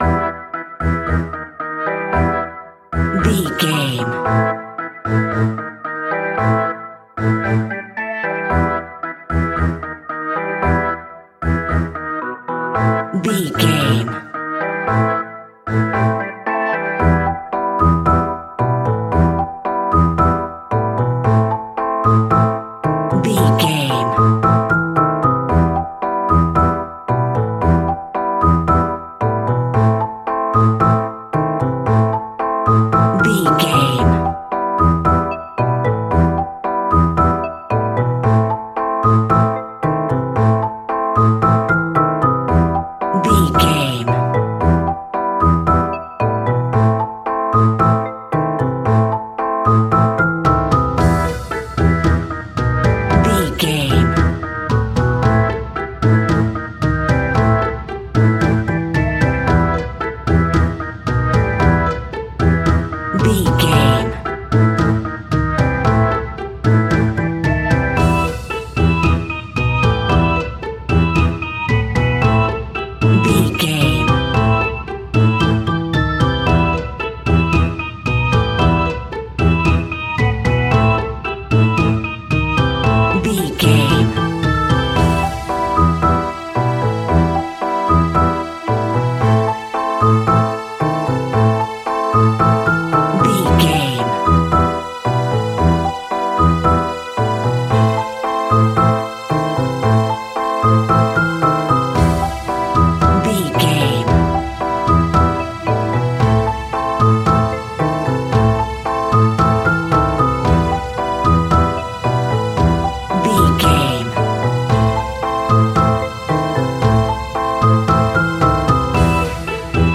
A great piece of royalty free music
Dorian
scary
ominous
dark
haunting
eerie
synthesizer
percussion
strings
spooky
horror music